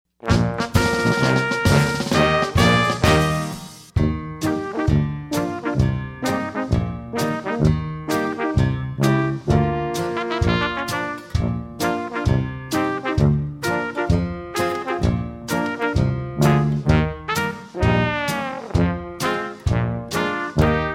Listen to a sample of this instrumental song.
Downloadable Instrumental Track